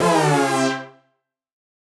Index of /musicradar/future-rave-samples/Poly Chord Hits/Ramp Down